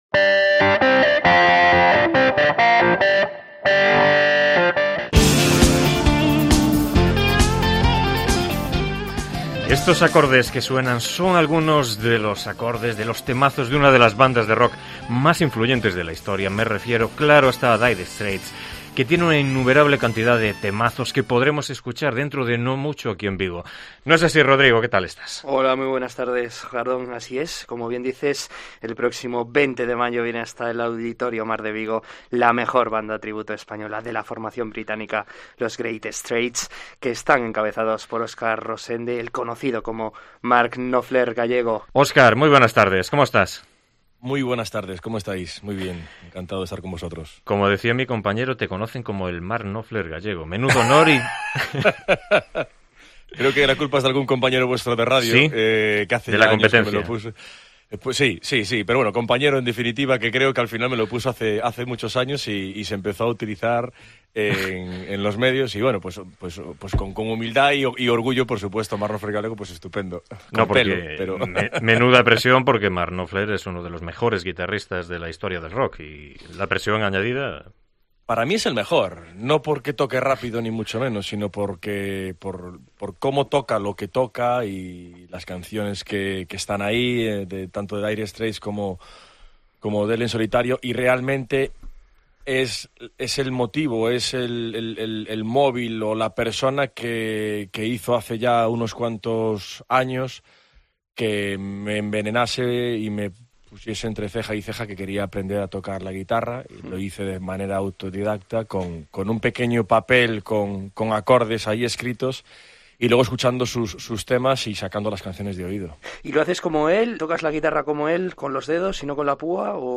ENTREVISTA La mejor banda tributo de los Dire Straits llega a Vigo el próximo mes de mayo
Con él hemos charlado en COPE Vigo y también nos ha interpretado en directo uno de los temas más conocidos de la banda.